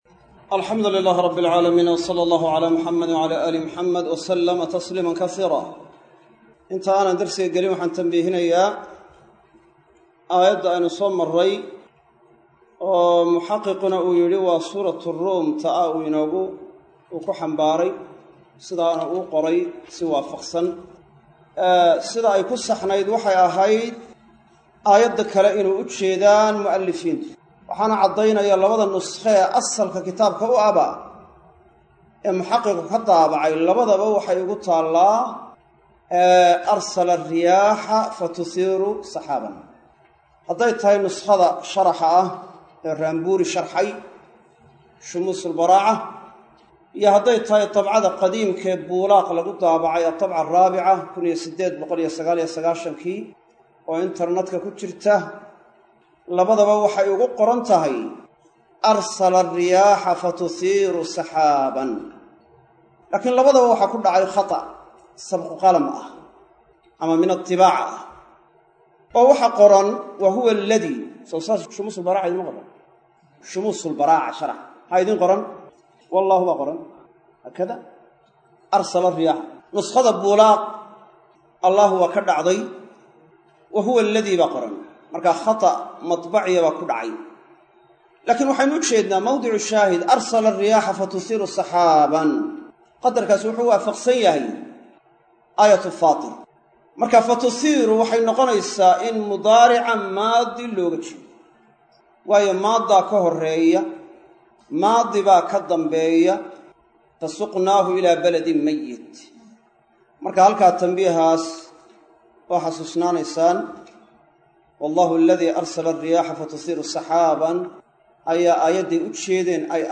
Duruusu Balaaqa - Darsiga 24aad - Manhaj Online |